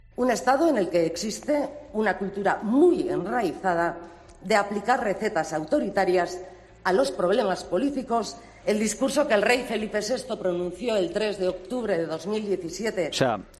Palabras de Aizpurua (Bildu) sobre el Rey